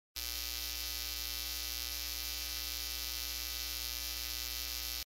• BUZZ ( Rumore generato dalla forte presenza di un elevato numero di armoniche in media ed alta frequenza ) ( a.1 ).
A.1 Buzz
60hz-audio.mp3